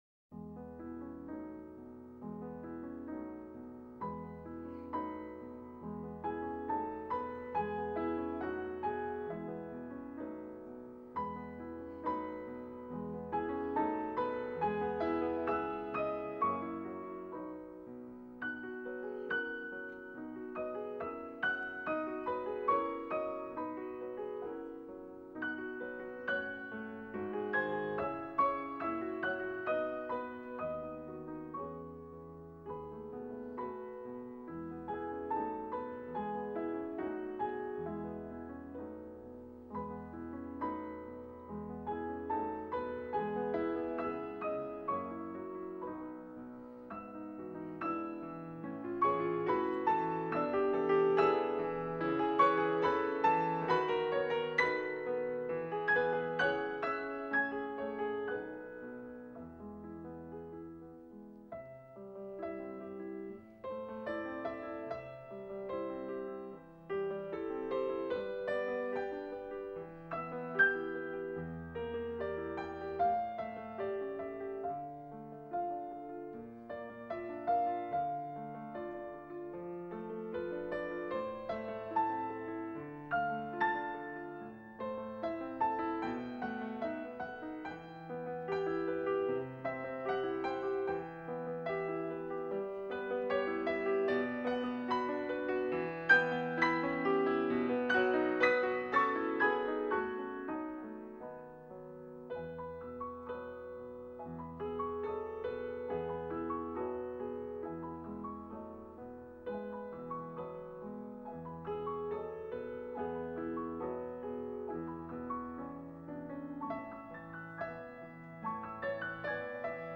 PIANO
ORIENTATION CLASSIQUE
Morceau : La suite Dolly, op.56 est un ensemble de six pièces pour piano à quatre mains, écrit par Gabriel Fauré entre 1894 et 1897.